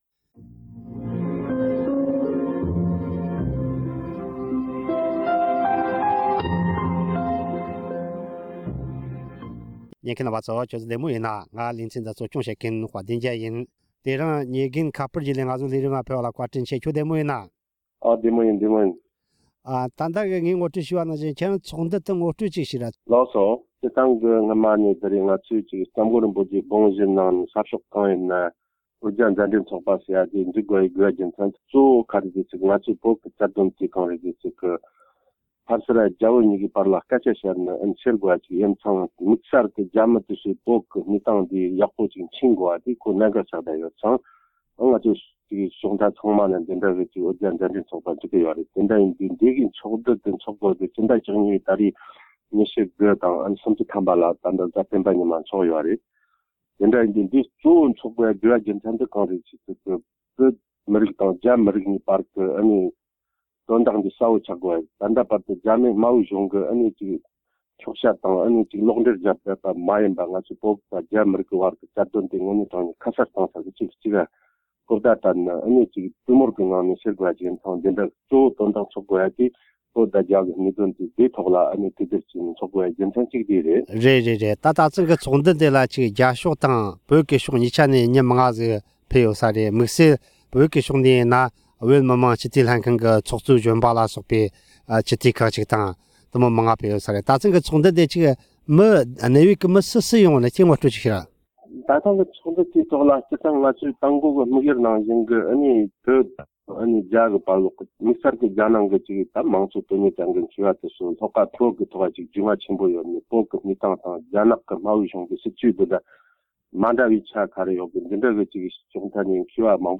ཉེ་ཆར་ཨོ་སི་ཀྲི་རེ་ལི་ཡར་རྒྱལ་སྤྱིའི་བོད་རྒྱའི་གྲོས་མོལ་ཚོགས་འདུ་ཞིག་ཚོགས་ཡོད་པའི་སྐོར་འབྲེལ་ཡོད་མི་སྣ་དང་གླེང་མོལ།